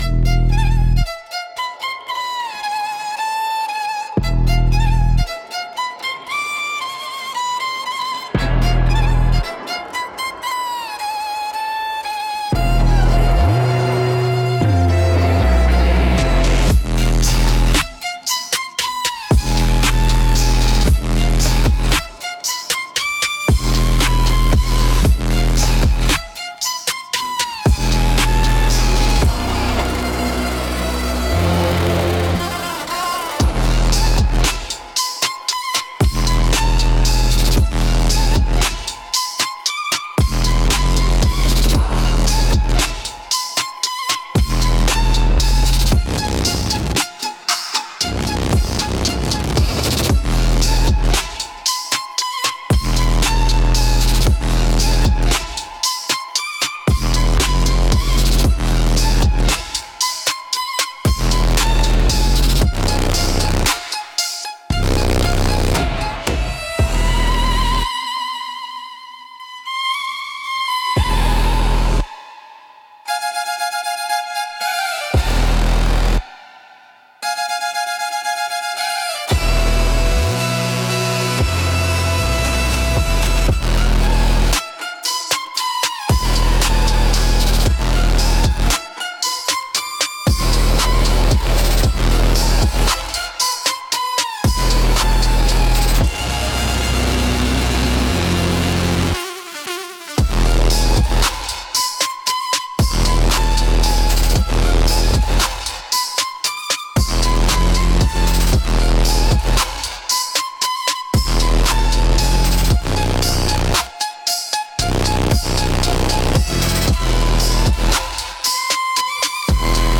Instrumental - Your Move, Darling 3.28